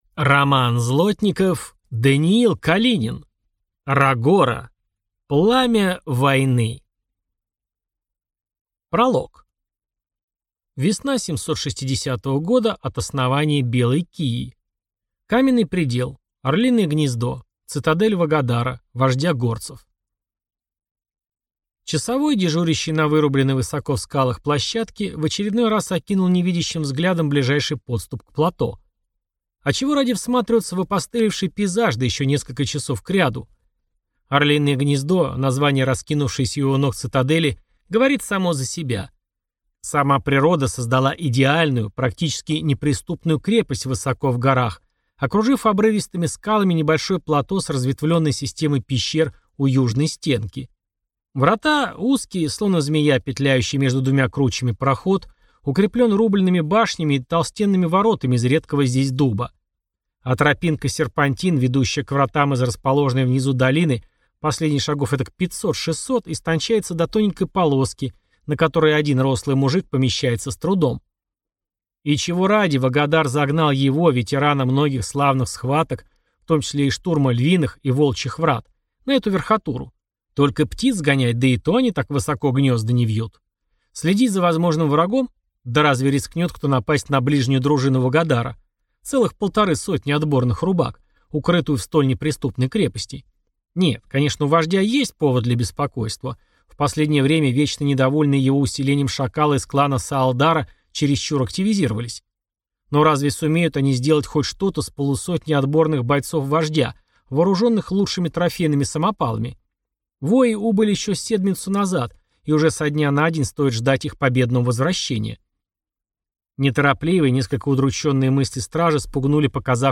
Аудиокнига Рогора. Пламя войны | Библиотека аудиокниг